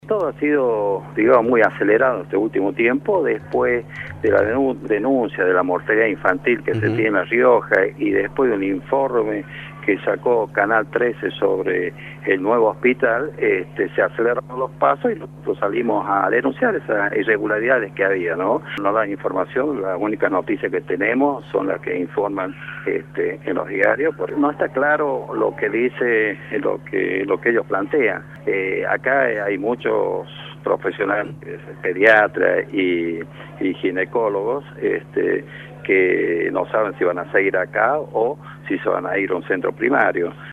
por Radio La Red